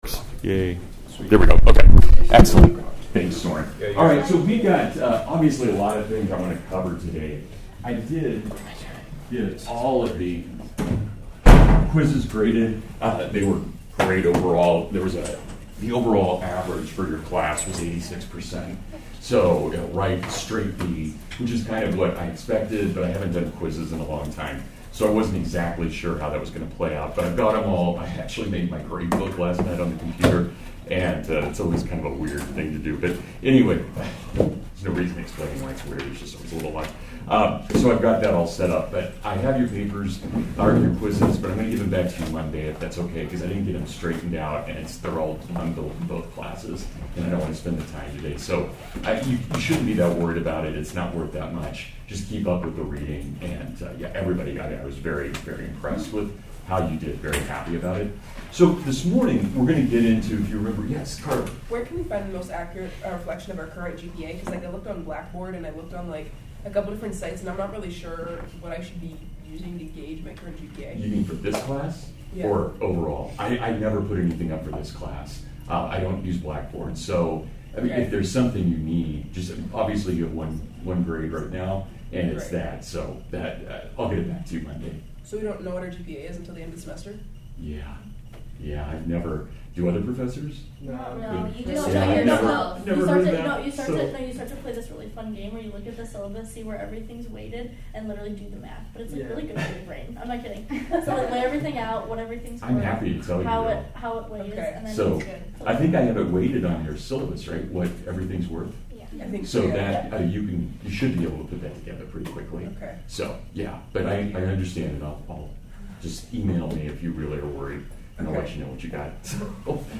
The Virtues and PreSocratics (Full Lecture)